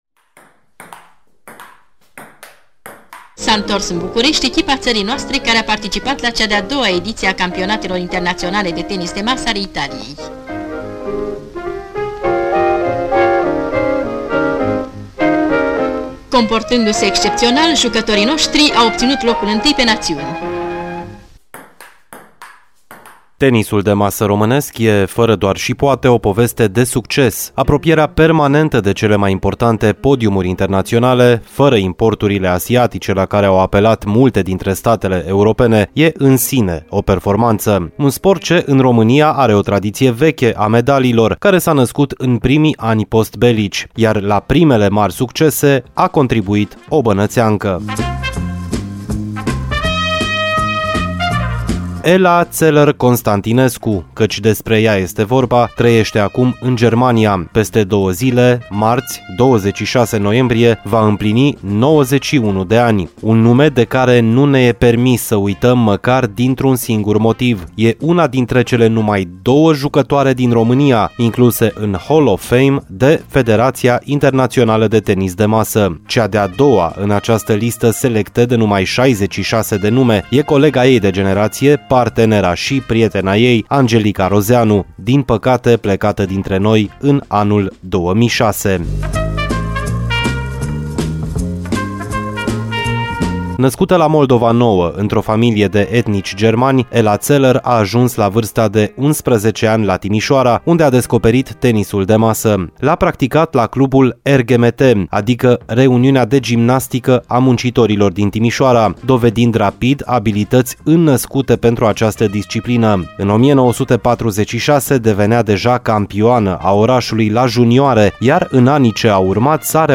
Reportajul dedicat Ellei Zeller Constantinescu